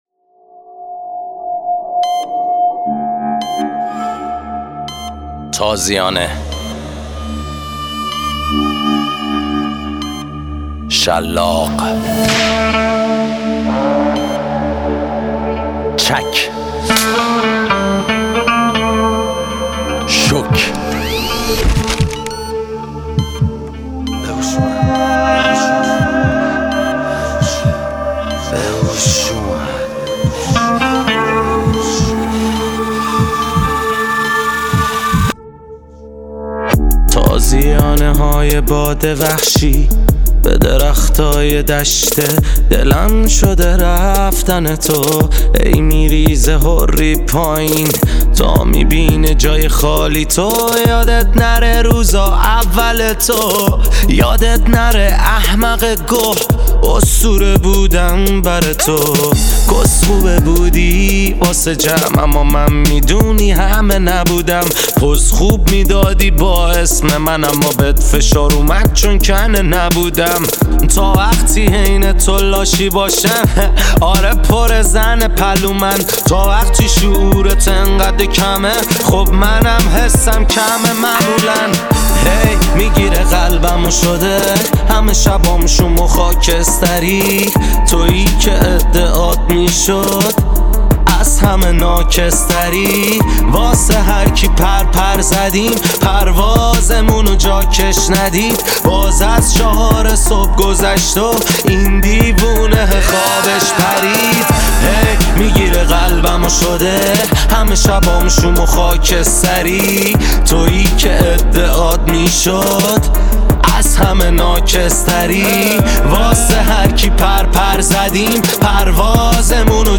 آراَندبی